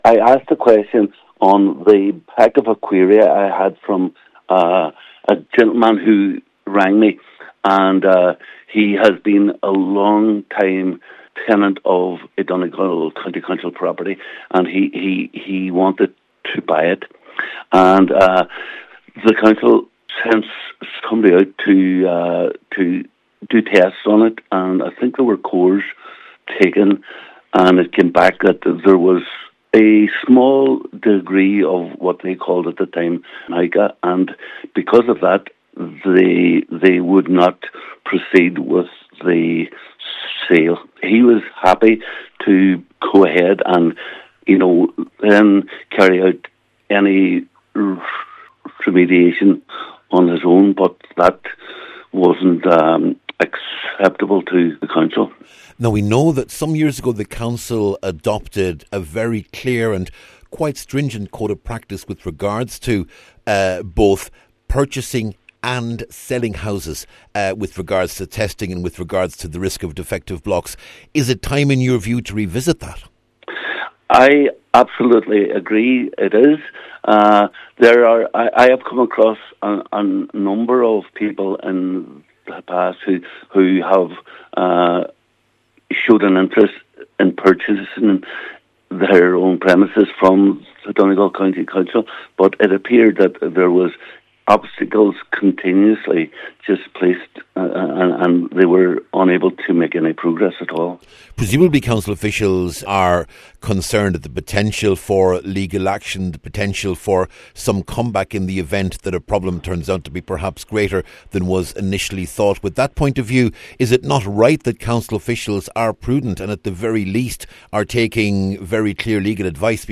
At a recent meeting of Inishowen Municipal District, Cllr Terry Crossan said there needs to be a new set of protocols drawn up to make it easier for people to buy their homes from the council.
Cllr Crossan says there have been cases where people have been refused permission to buy their home……..